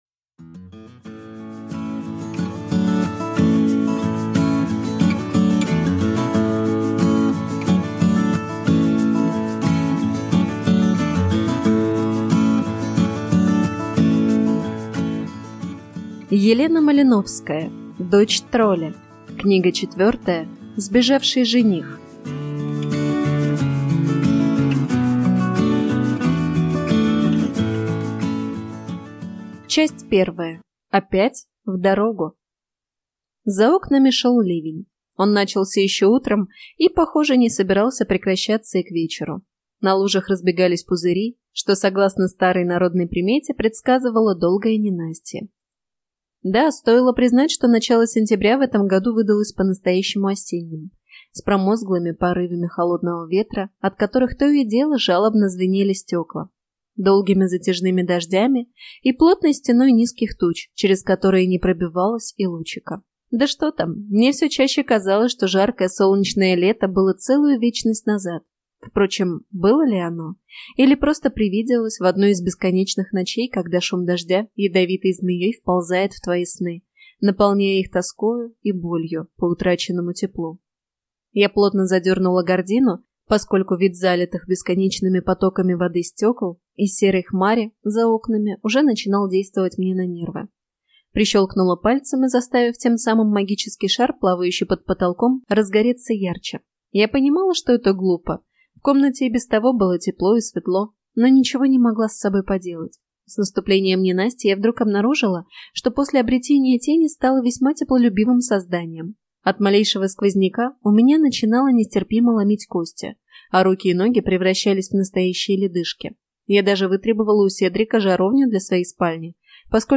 Аудиокнига Сбежавший жених | Библиотека аудиокниг